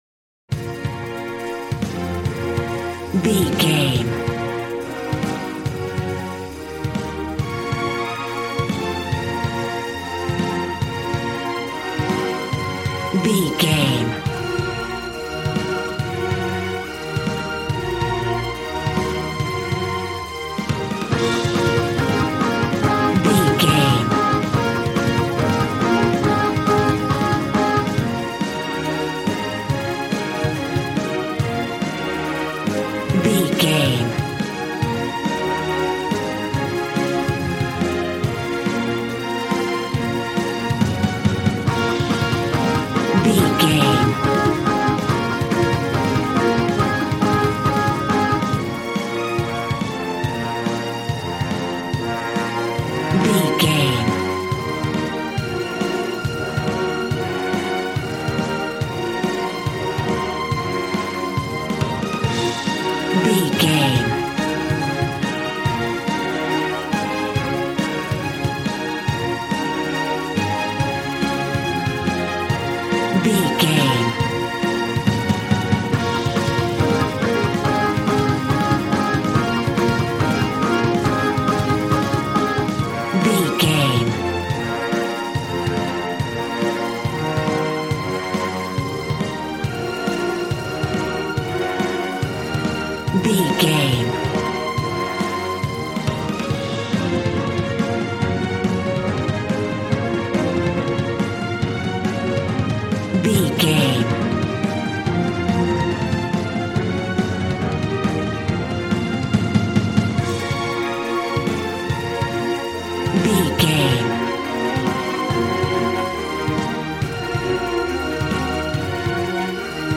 Aeolian/Minor
B♭
strings
violin
brass